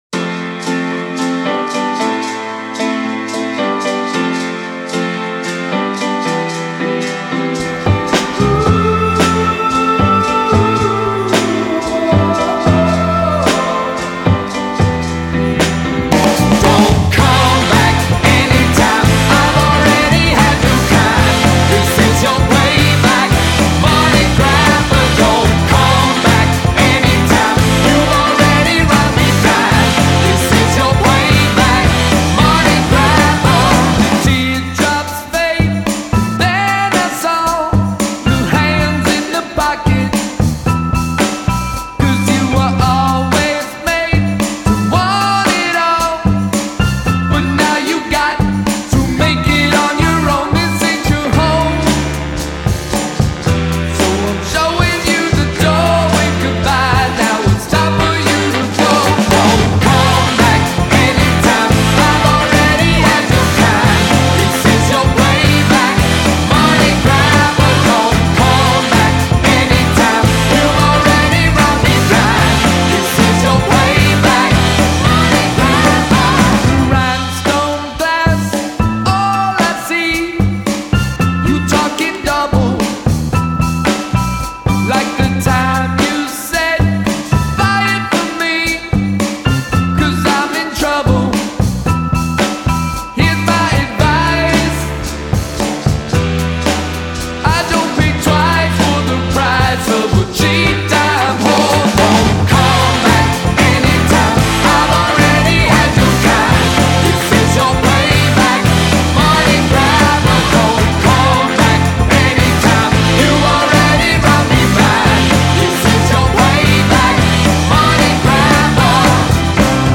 I like soul music from the 60s.
No guitar?